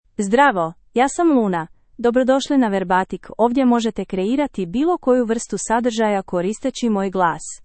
Luna — Female Croatian (Croatia) AI Voice | TTS, Voice Cloning & Video | Verbatik AI
LunaFemale Croatian AI voice
Luna is a female AI voice for Croatian (Croatia).
Voice sample
Listen to Luna's female Croatian voice.
Female